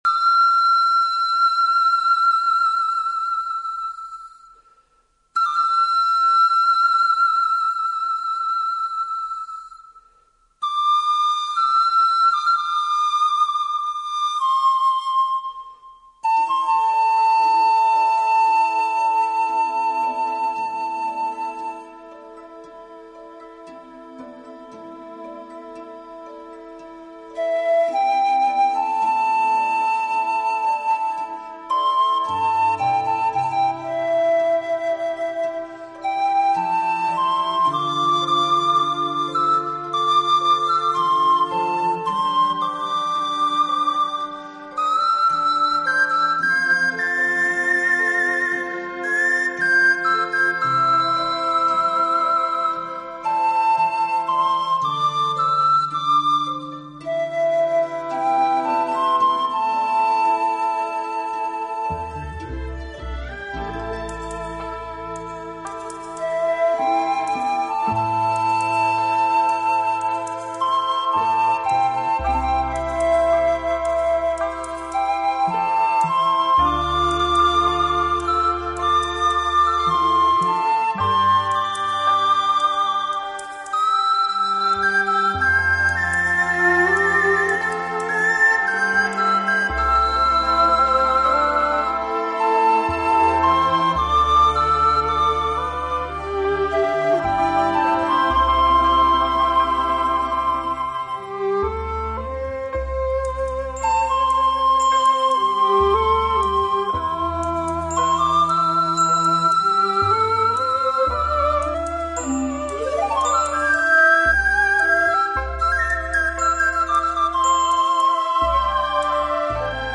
笛子演奏家